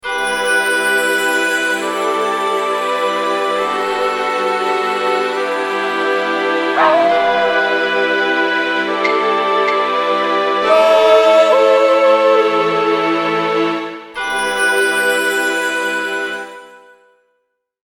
夜。ご近所からジャングルまで。
Tags: ジャングル / ターザン / 声 / 夜 / 犬 / 遠吠え / 面白い